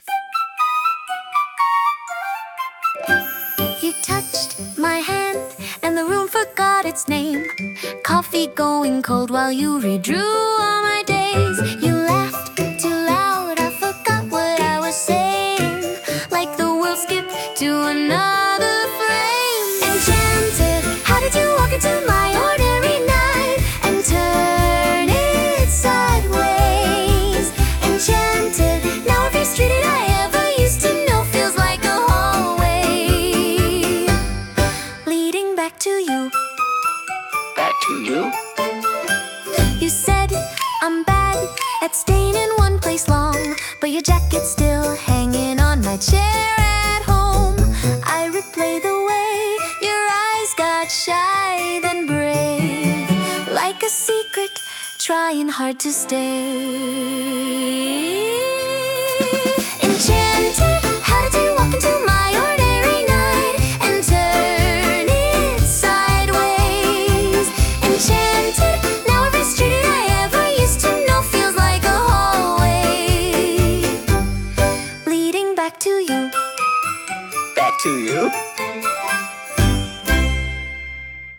Instrumental / 歌なし
気まぐれなピアノや風変わりな効果音が、選手のコミカルで可愛らしい表情を自然と引き出してくれるはず。